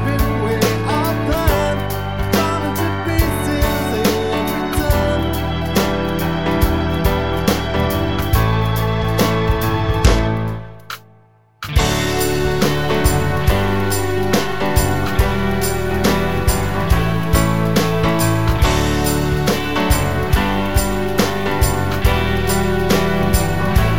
Down Two Semitones Pop (2000s) 3:53 Buy £1.50